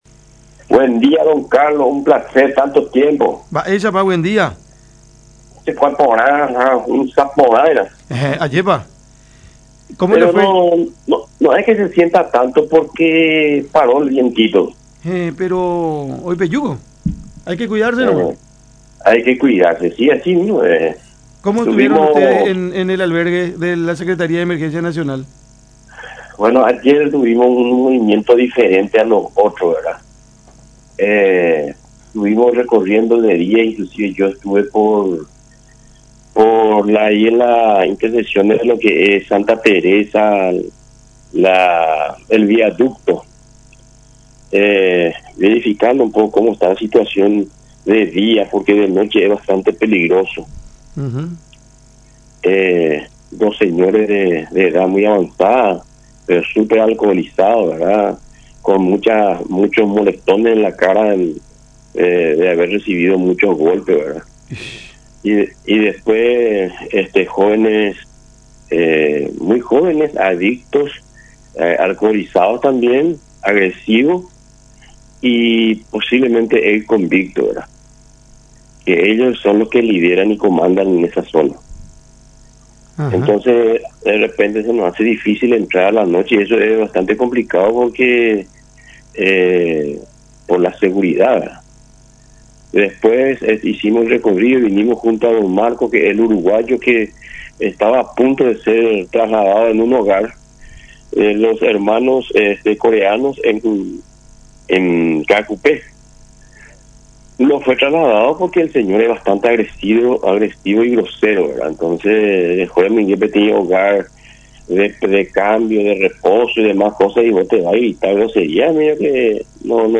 en diálogo con Cada Mañana a través de La Unión.